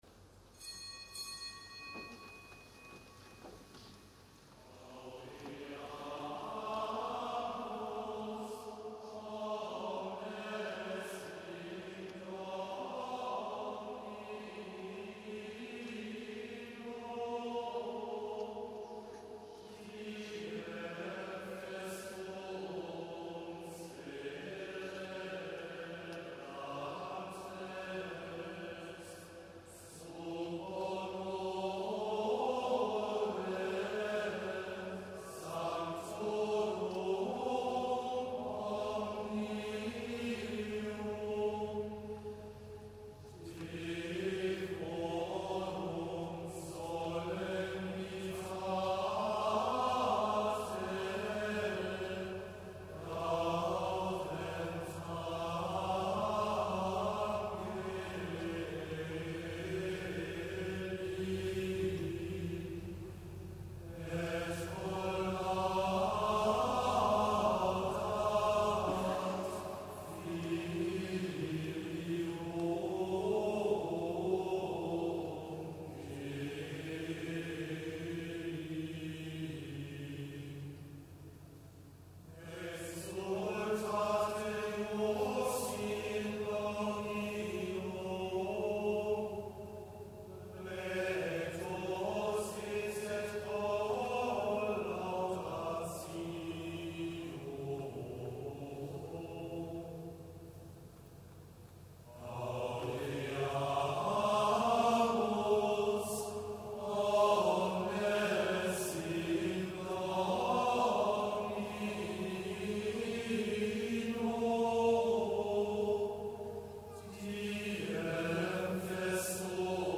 Pontifikalamt am Hochfest Allerheiligen
Pontifikalamt aus dem Kölner Dom am Hochfest Allerheiligen mit Erzbischof Rainer Maria Kardinal Woelki.